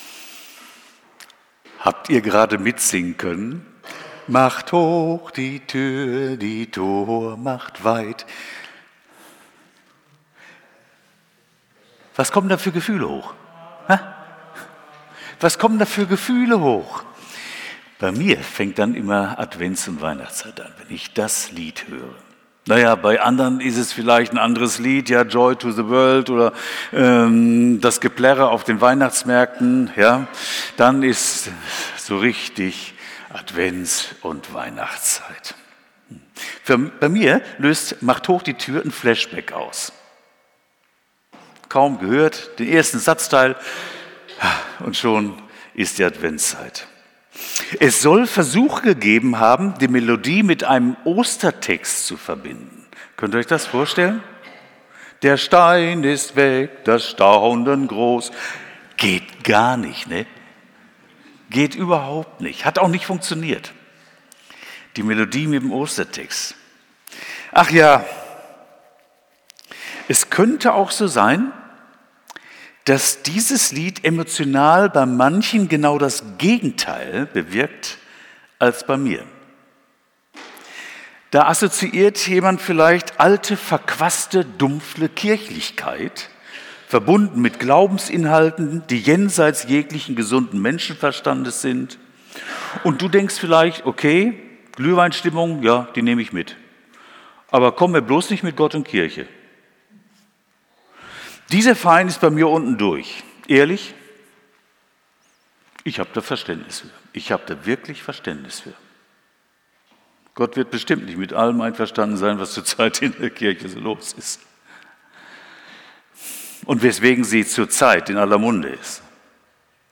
Predigt vom 30.11.2025